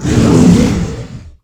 combat / creatures / dragon / he / hurt3.wav
hurt3.wav